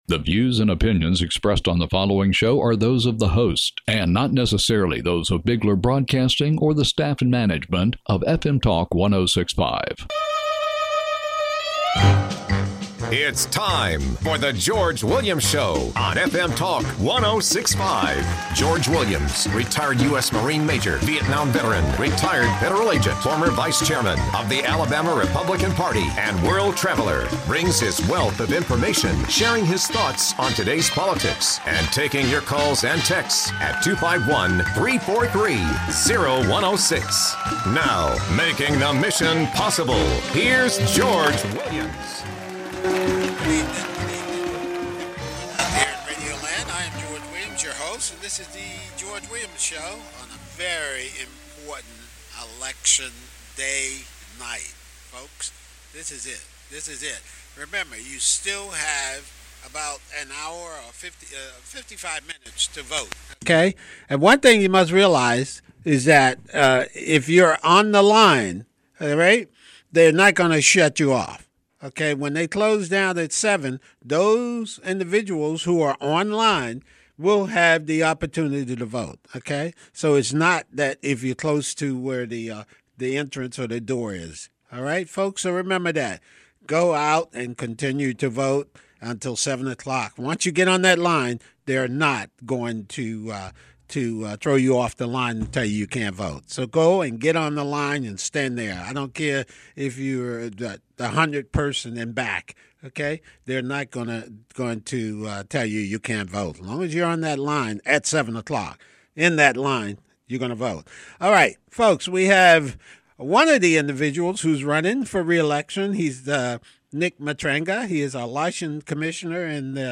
his guests tonight are License Commissioner Nick Matranga and Jerry Carl, running for the Congressional District 1 seat